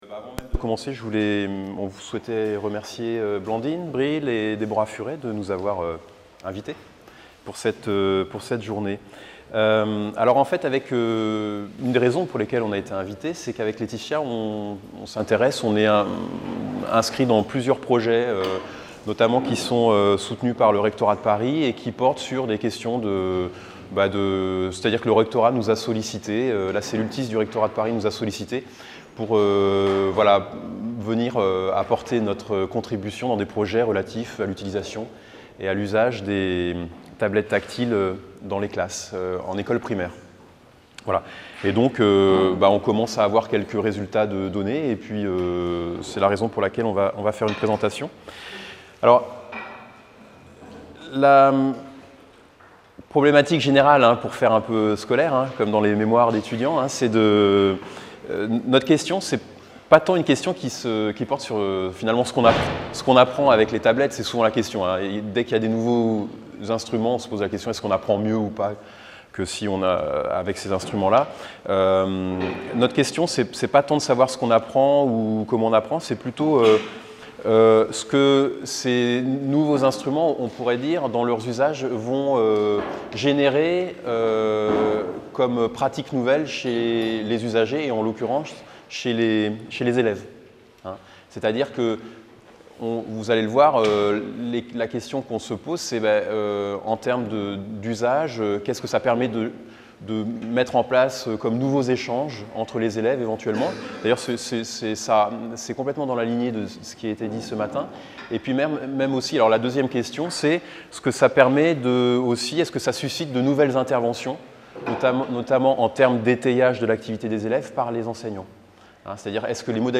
Journée d’étude organisée par l’EHESS en collaboration avec le ministère de l’éducation nationale 8 avril 2014, Salle des Conférences, Lycée Henri IV, 75005 Paris Maintenant, les objets communicants font partie de l’environnement des enfants dès leur naissance. Si ces objets ne sont pas entre les mains des jeunes enfants, ils sont omniprésents chez leurs entourages et dans le monde matériel et culturel qui les entoure.